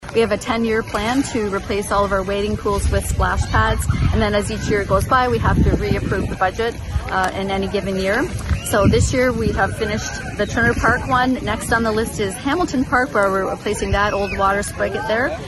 Councillor Lesley Parnell says this particular project was about five years in the making.